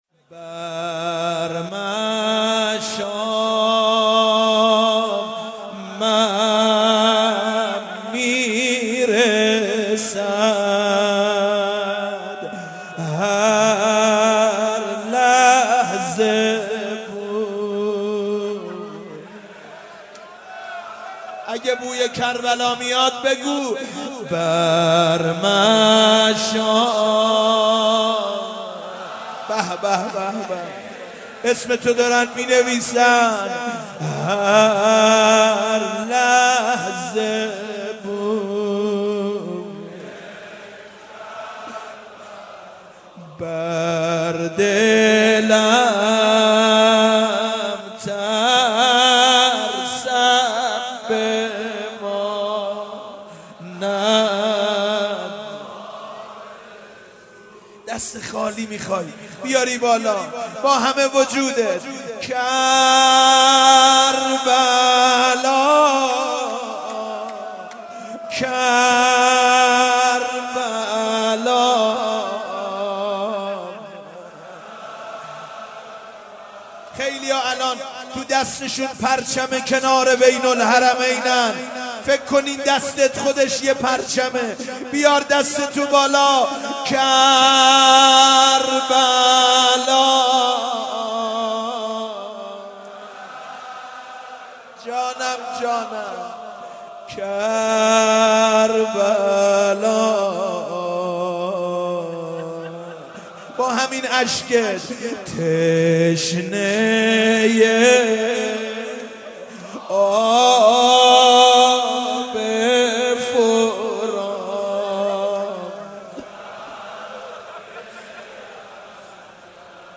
مناسبت : اربعین حسینی
قالب : روضه